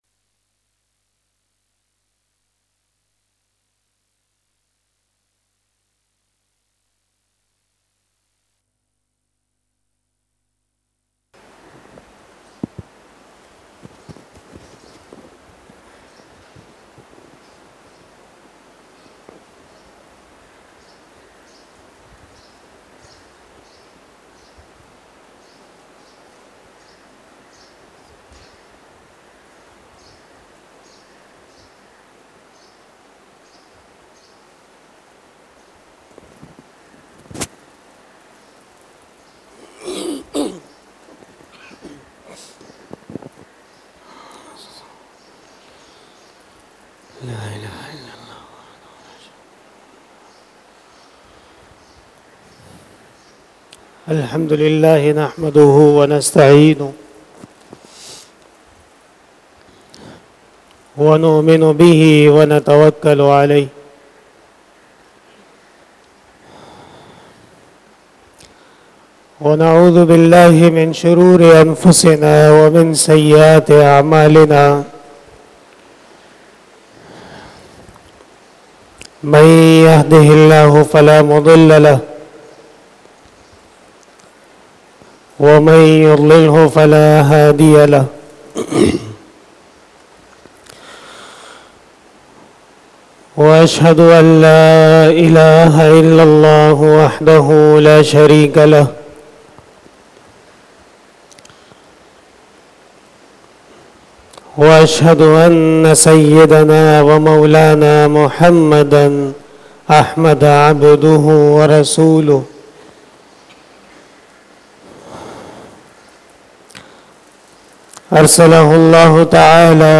39 BAYAN E JUMA TUL MUBARAK 07 October 2022 (11 Rabi ul Awwal 1444H)